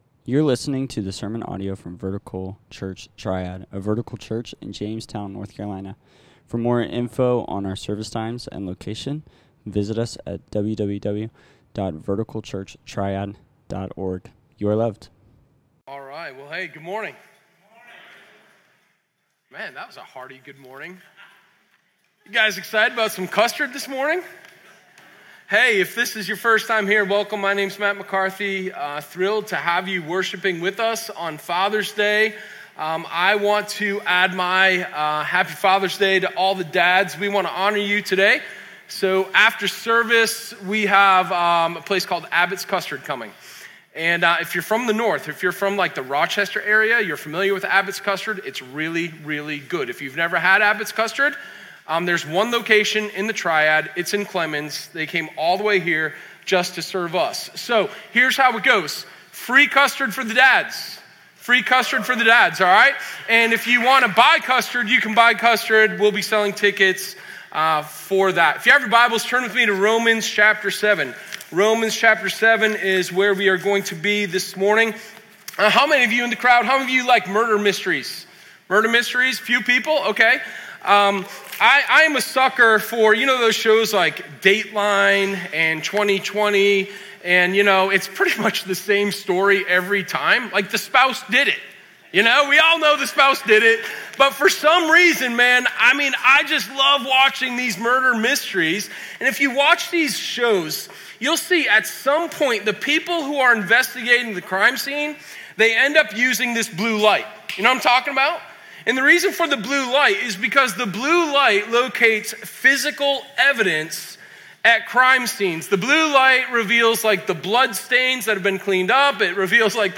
Sermon0611_Exposed.m4a